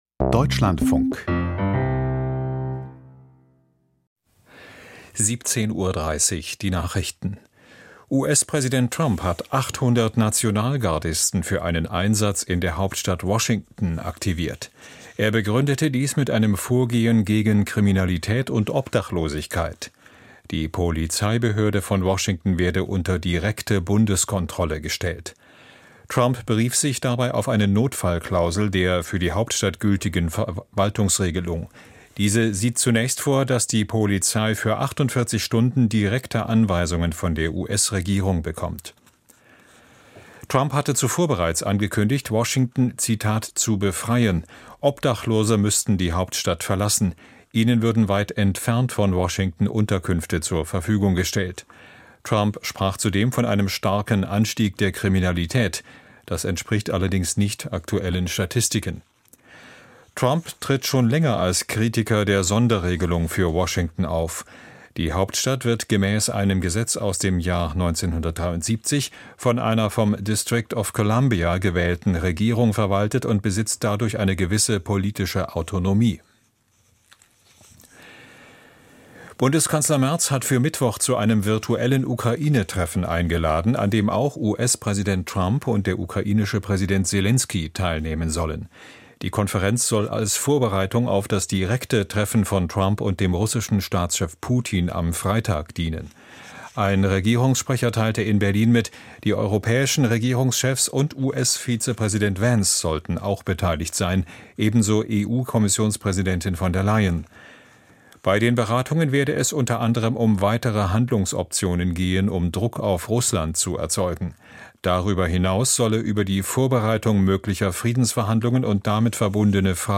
Die Nachrichten vom 11.08.2025, 17:30 Uhr